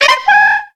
Cri de Pijako dans Pokémon X et Y.